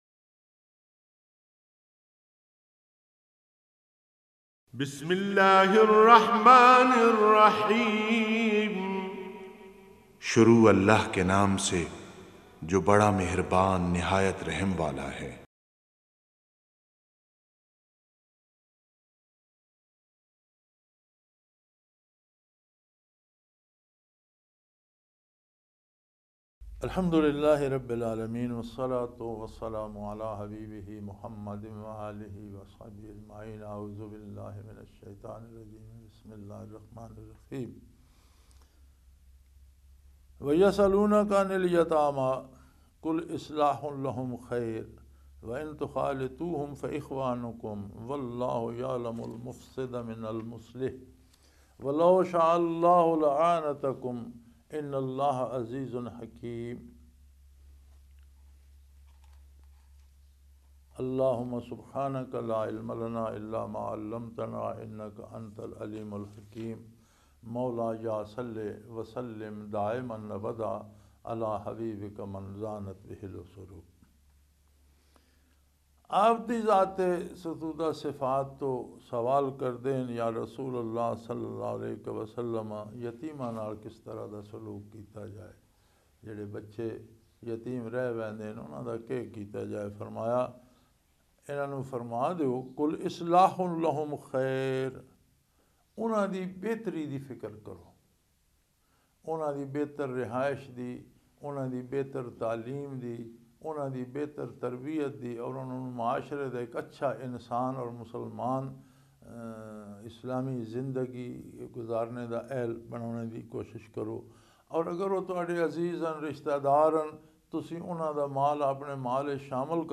Punjabi Tafseer in Munara, Chakwal, Pakistan